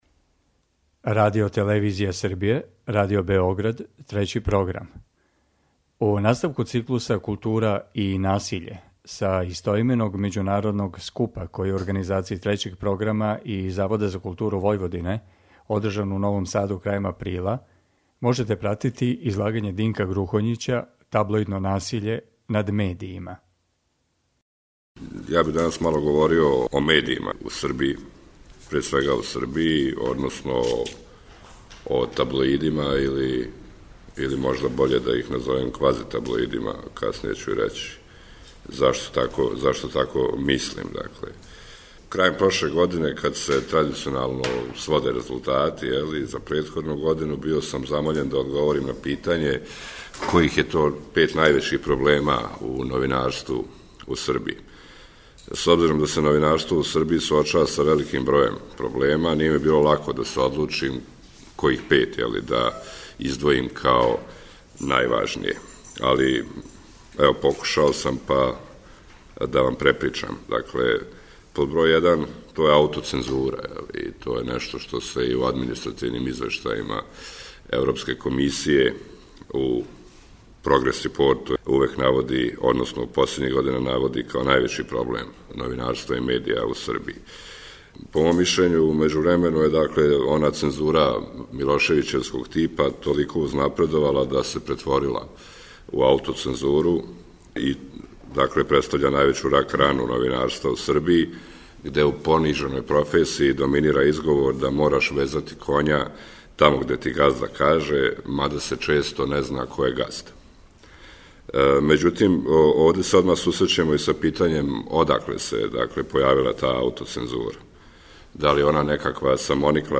У циклусу КУЛТУРА И НАСИЉЕ, који емитујемо средом, са истоименог научног скупа који су, у Новом Саду крајем априла, организовали Трећи програм и Завод за културу Војводине, емитујемо прилоге са овога скупа и разговоре о излагањима.